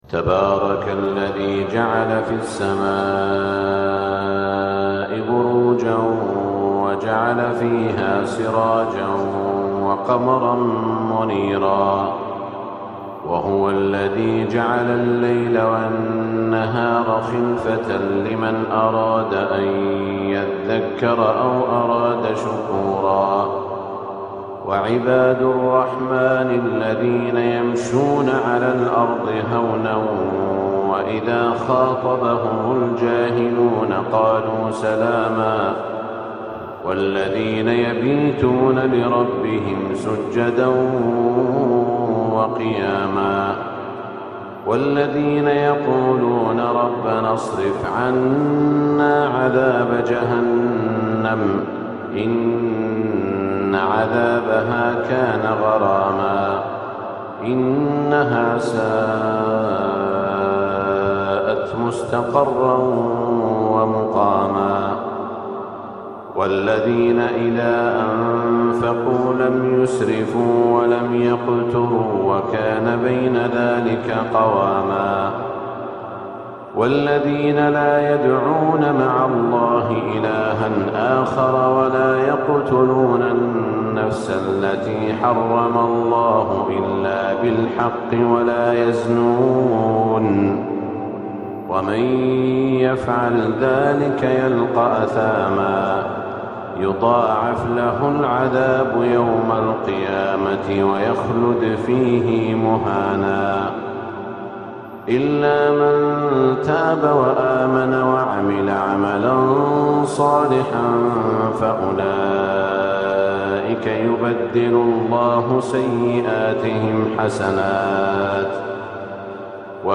Recitation Of Quran 📖 🎧💫 Sound Effects Free Download